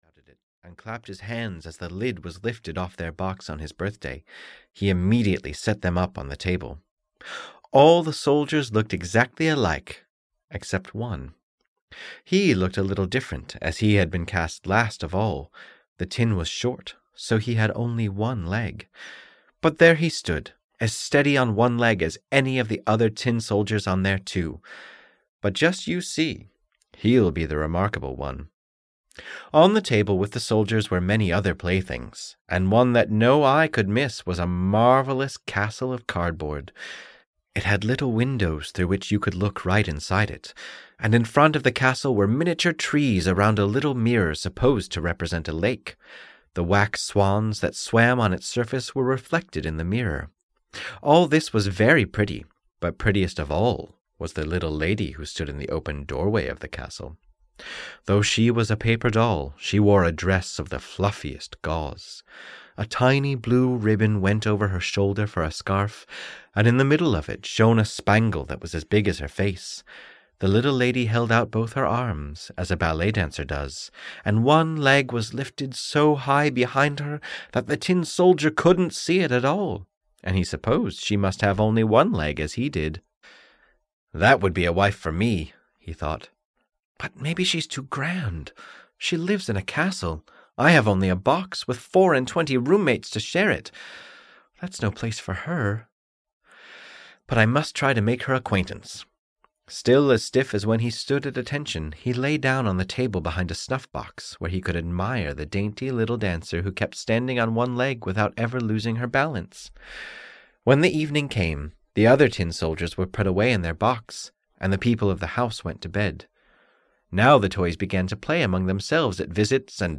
Tales About Love (EN) audiokniha
Ukázka z knihy